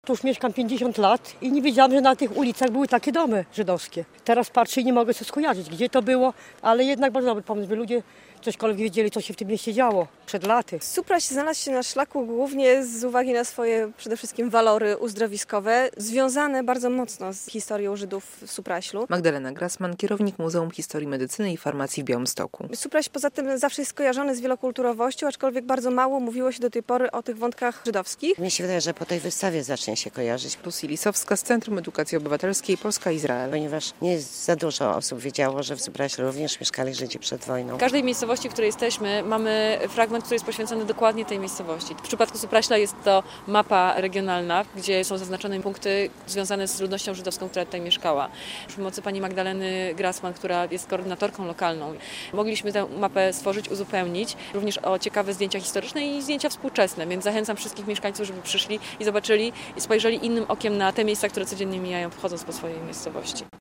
W Supraślu stanęło mobilne muzeum - relacja